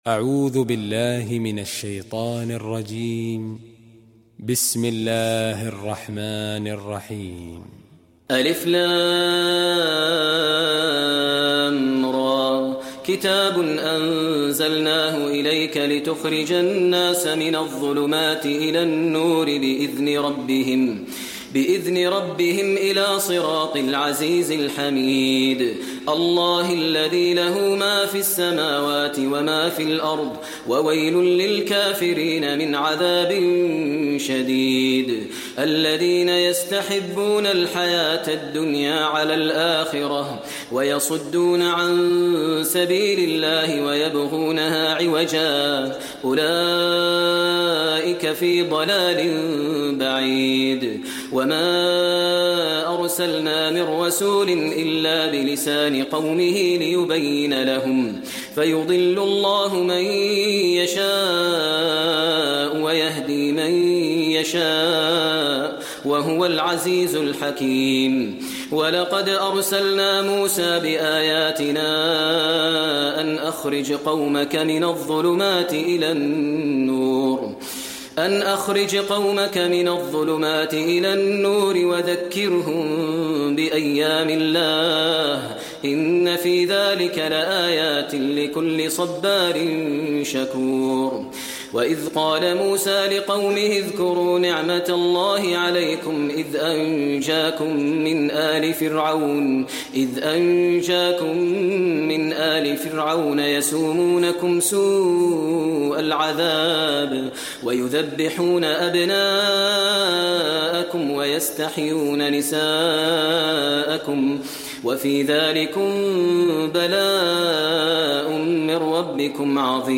المكان: المسجد النبوي إبراهيم